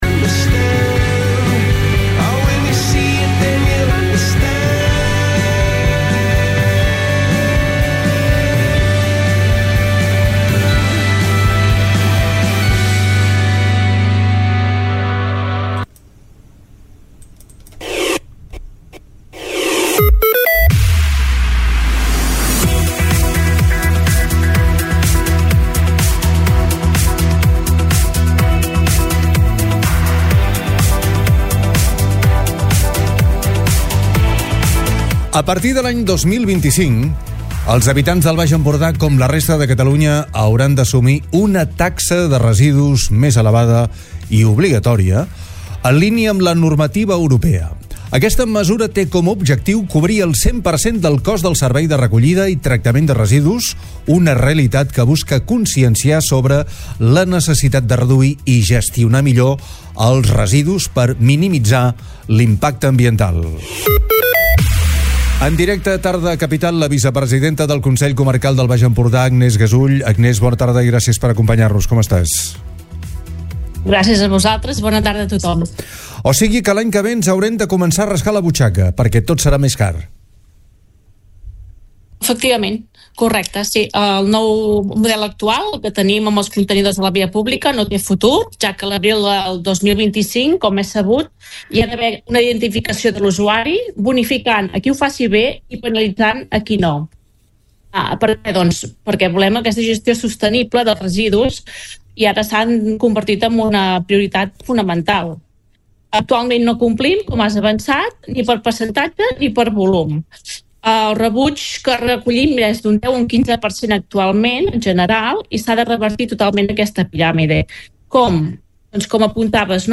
la vice presidenta del consell comarcal, Agnès Gasull